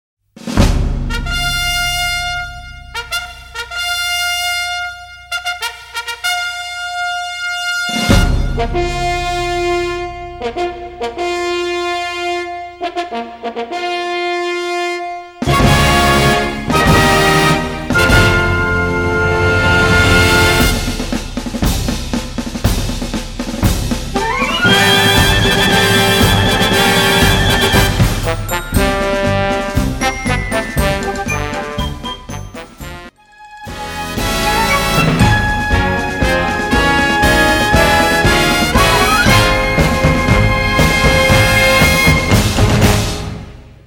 難易度 分類 並足９６〜１１６ 時間 ３分３０秒
編成内容 大太鼓、中太鼓、小太鼓、シンバル、トリオ 作成No １５４